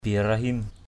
/bi-ra-hɪm/ (d.) Ibrahim, Abraham.